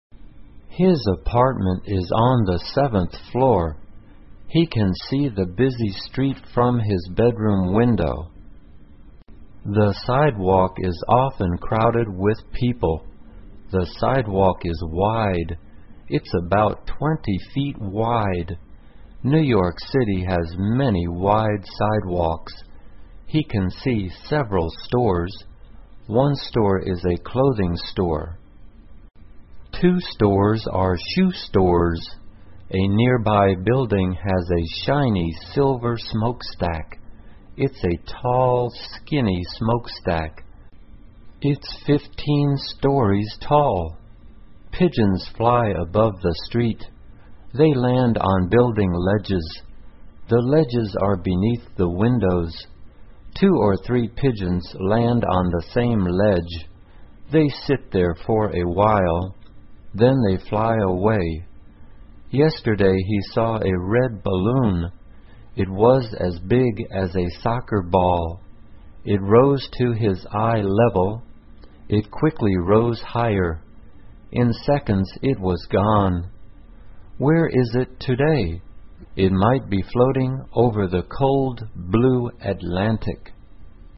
慢速英语短文听力 他的窗外（2） 听力文件下载—在线英语听力室